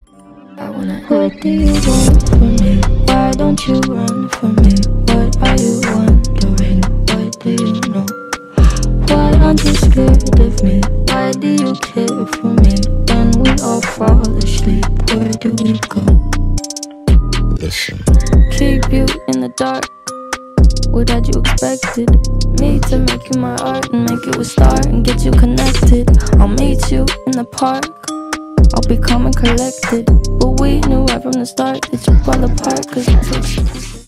• Качество: 320 kbps, Stereo
Ремикс
грустные